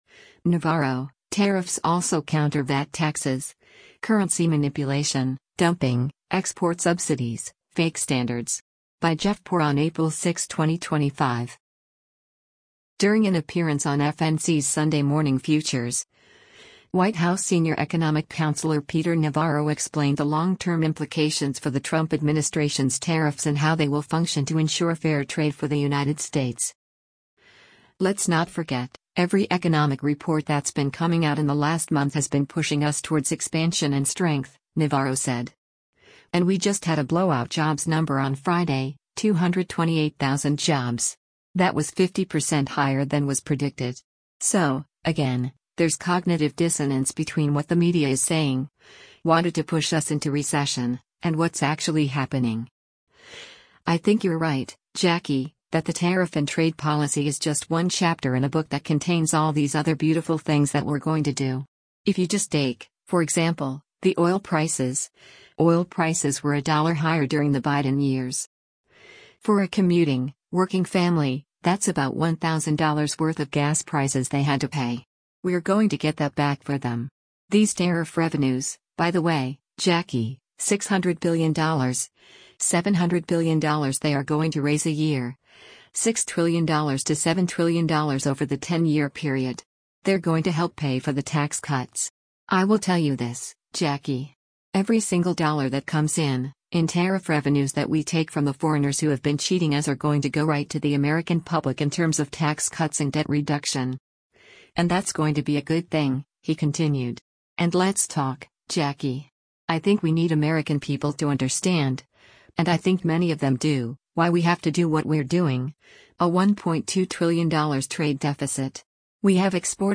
During an appearance on FNC’s “Sunday Morning Futures,” White House senior economic counselor Peter Navarro explained the long-term implications for the Trump administration’s tariffs and how they will function to ensure fair trade for the United States.